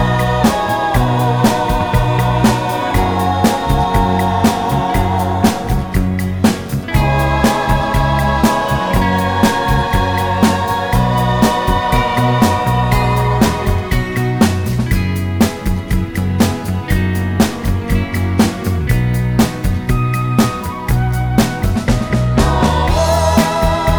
no Backing Vocals Crooners 2:39 Buy £1.50